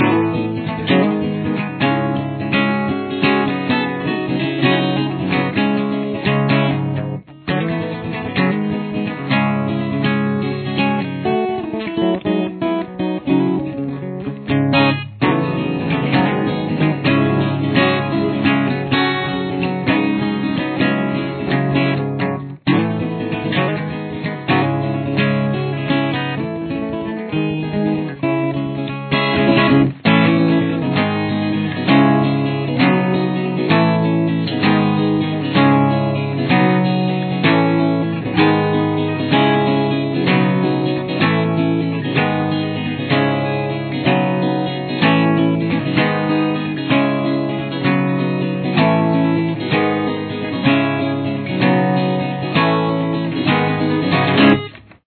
CAPO – Fourth Fret
Chorus 3 ( with Outro )